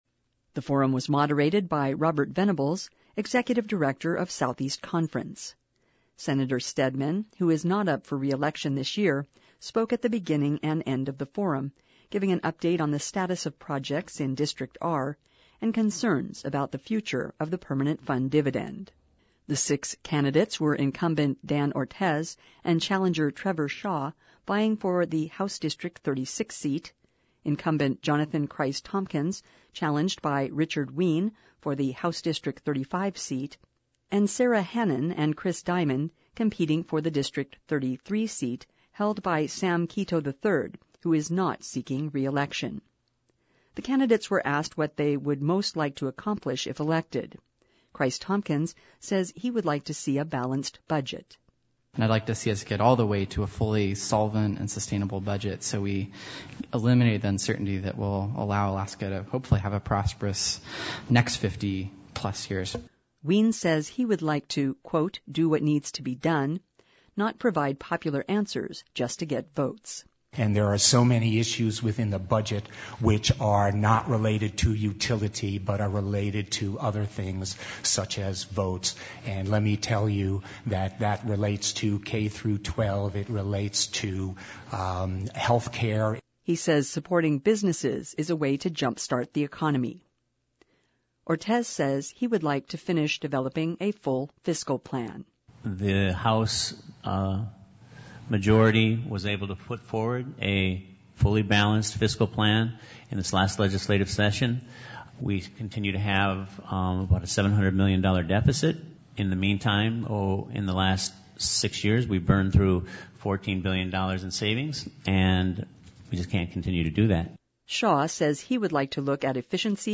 A legislative leaders forum was Thursday afternoon at Southeast Conference in Ketchikan.